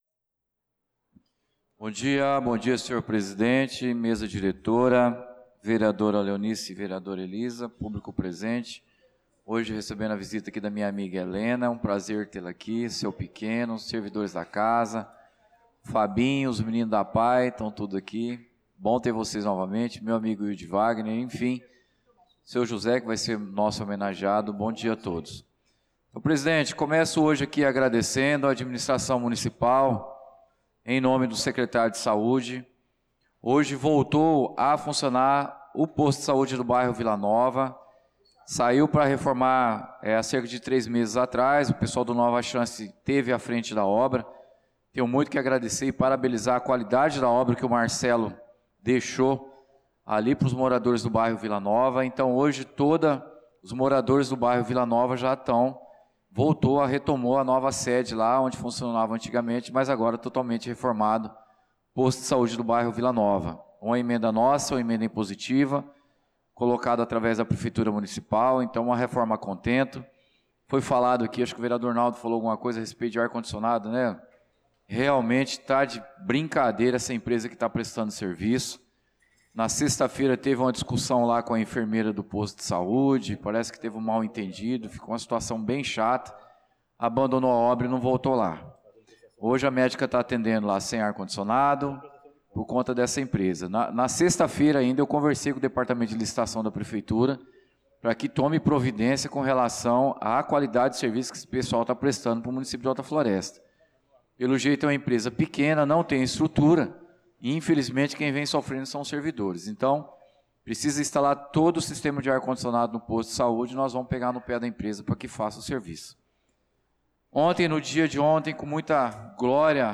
Pronunciamento do vereador Claudinei de Jesus na Sessão Ordinária do dia 26/05/2025